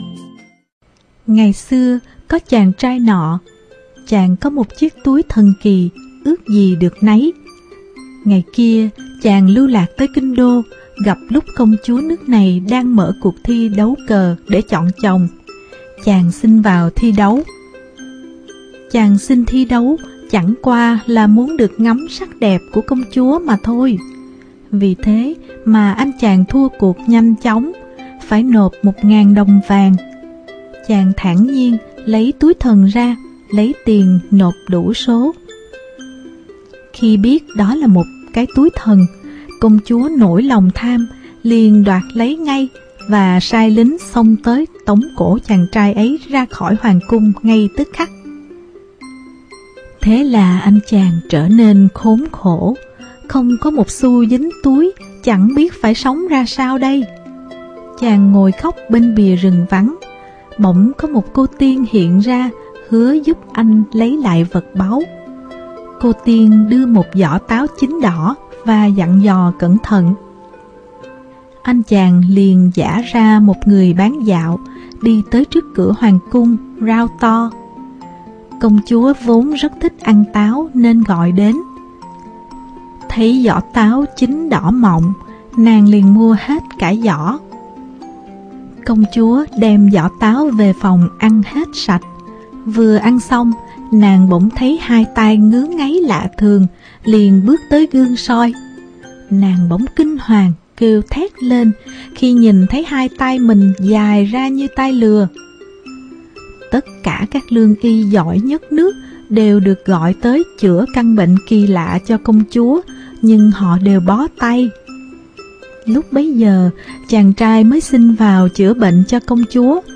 Sách nói | CÔNG CHÚA TAI LỪA